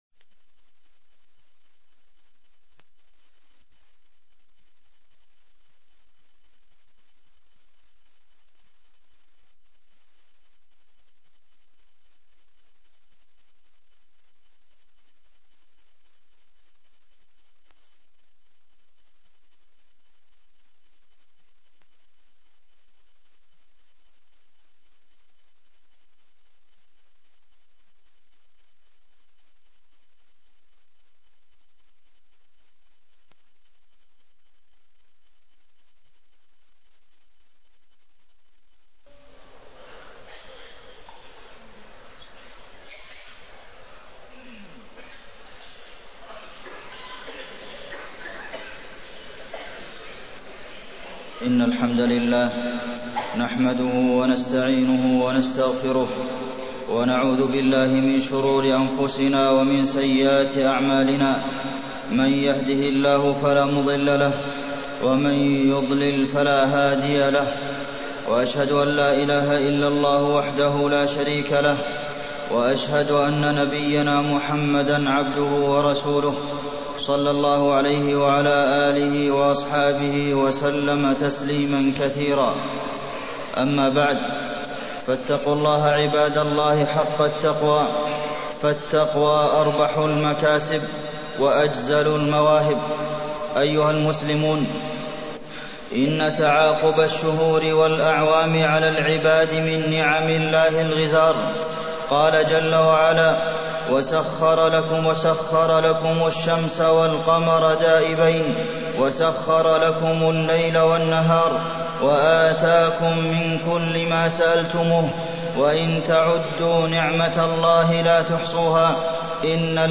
تاريخ النشر ٤ محرم ١٤٢٤ هـ المكان: المسجد النبوي الشيخ: فضيلة الشيخ د. عبدالمحسن بن محمد القاسم فضيلة الشيخ د. عبدالمحسن بن محمد القاسم محاسبة النفس في العام المنصرم